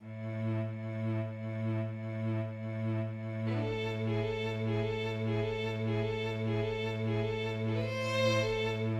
Le second élément thématique est passionné, plein d’élan qui, selon le compositeur, représente Alma ou l’idée qu'il se fait de son épouse[7],[4],[6].